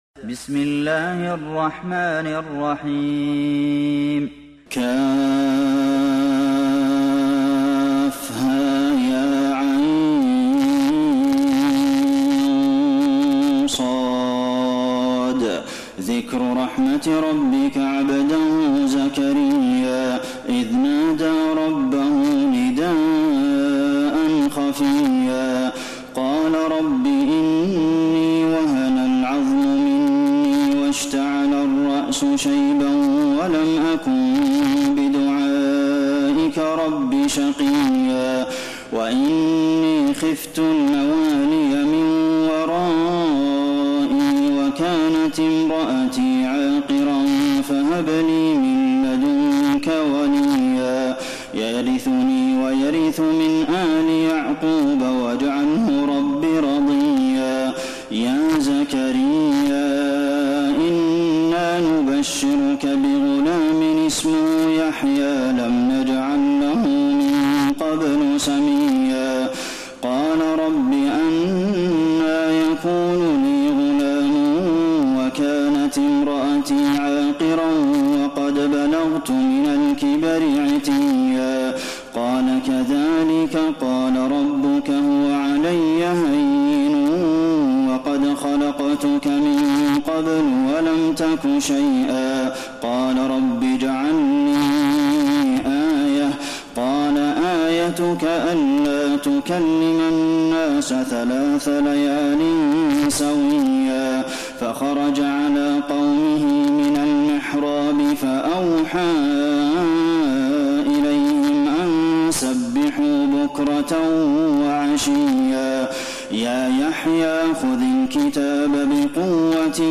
تراويح الليلة الخامسة عشر رمضان 1432هـ سورة مريم كاملة و طه (1-76) Taraweeh 15 st night Ramadan 1432H from Surah Maryam and Taa-Haa > تراويح الحرم النبوي عام 1432 🕌 > التراويح - تلاوات الحرمين